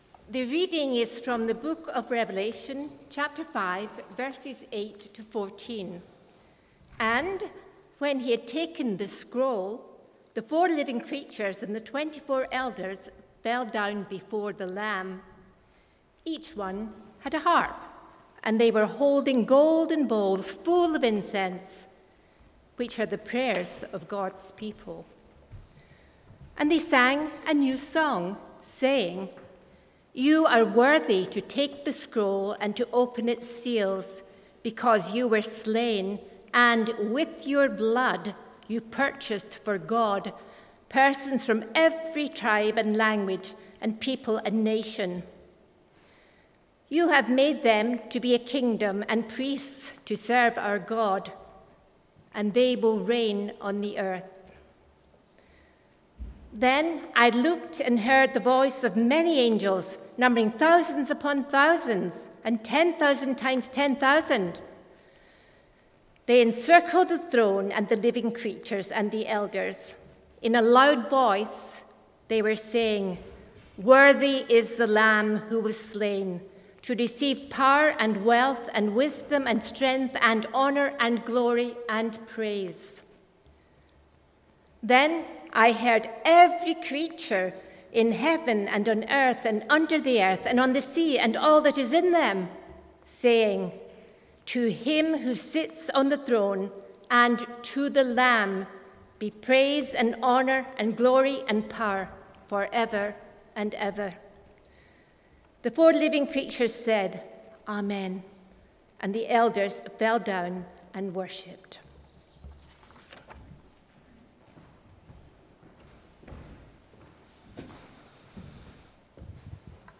Revelation 5:8-14 Service Type: Sunday Morning « Have we found the way out of our room?